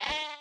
animalia_sheep_hurt.ogg